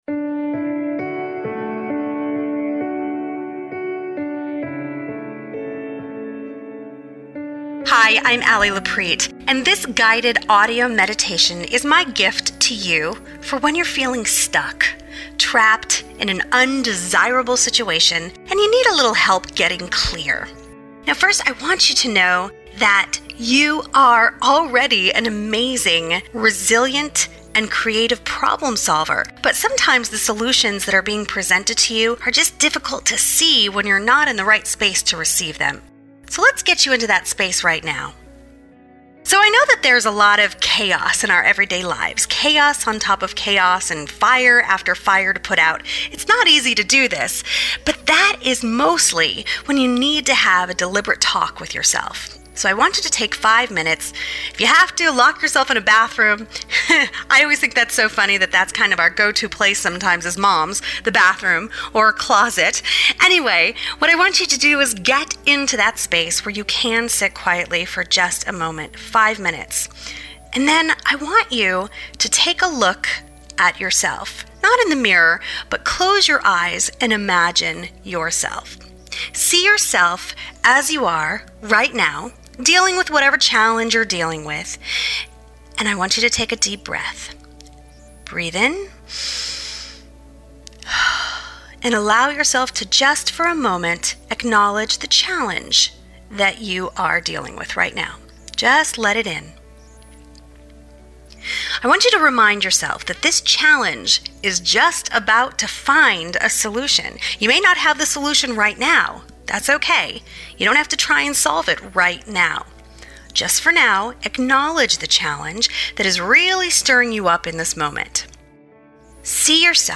This BONUS Guided Meditation:
ThisLittleParent_GuidedMeditation.mp3